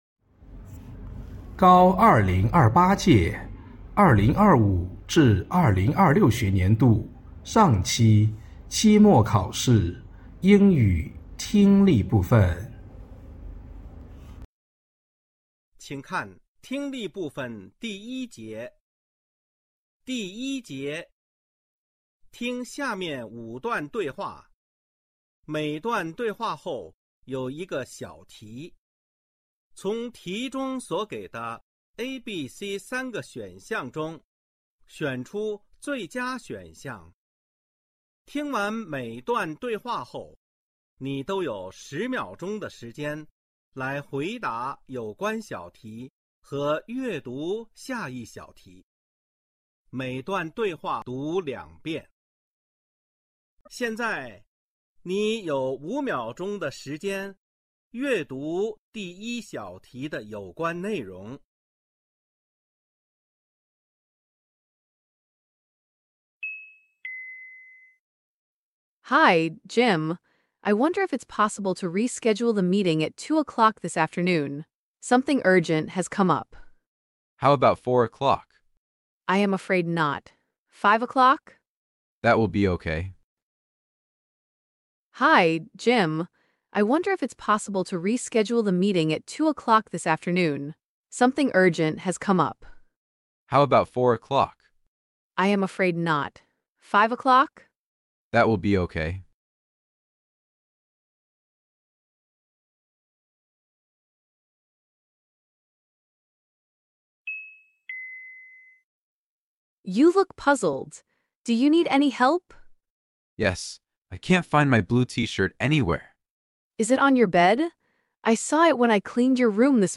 成都七中2025-2026学年高一上期末考试英语听力.mp3